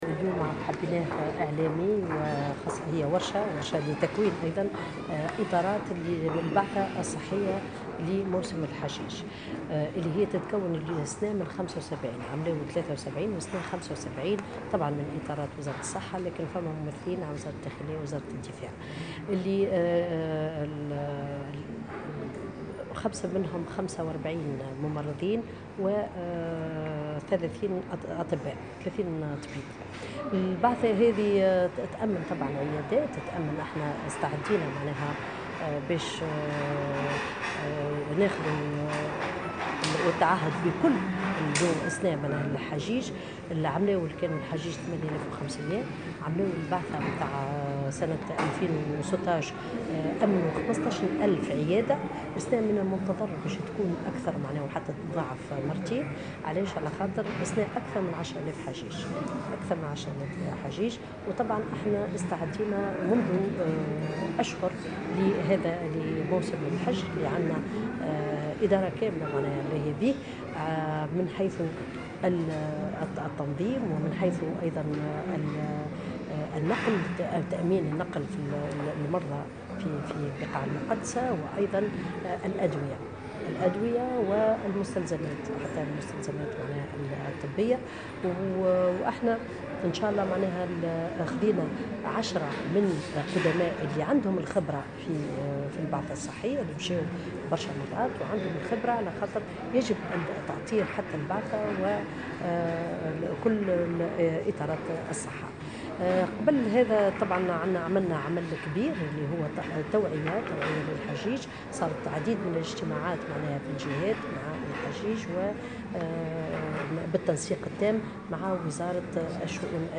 أكدت وزيرة الصحة سميرة مرعي في تصريح لمراسلة الجوهرة "اف ام" اليوم الجمعة خلال إشرافها على افتتاح اليوم الإعلامي والتكويني لأعضاء البعثة الصحية لموسم الحج أن عدد إطارات البعثة ارتفع هذا العام إلى 75 فردا.